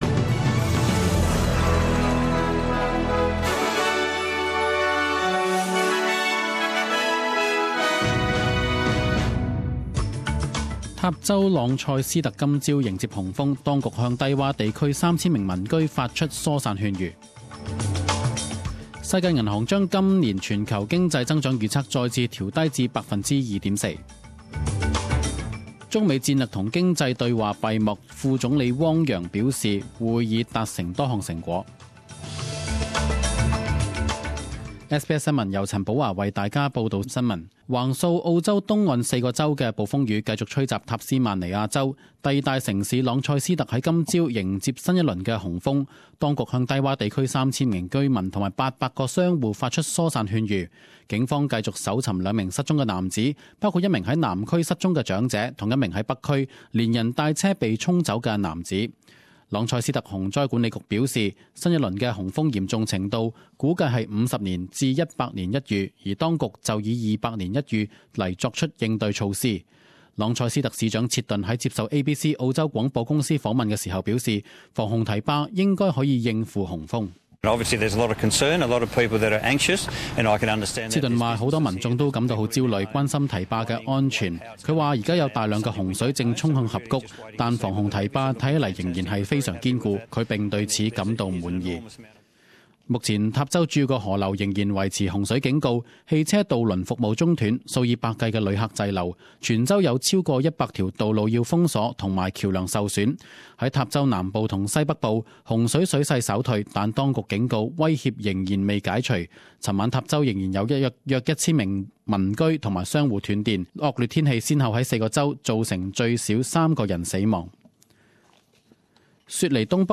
十点钟新闻报导 （六月八日）